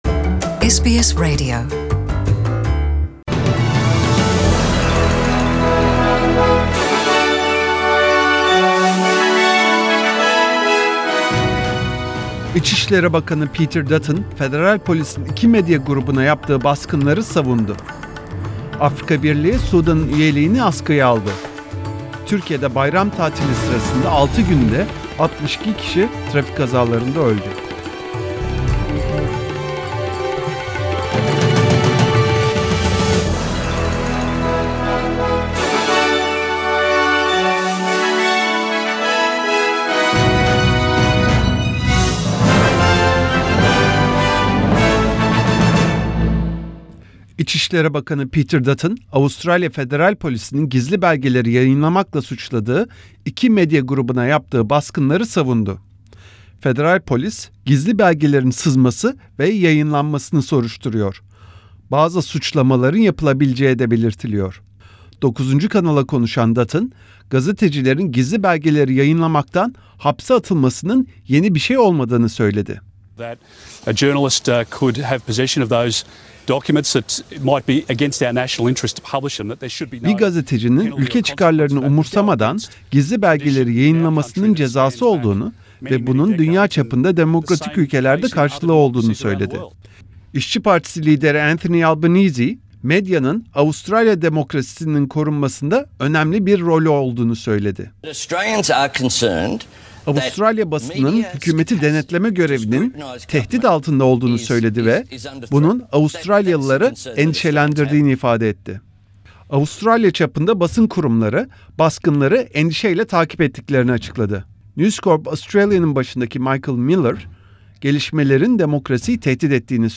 SBS Turkish News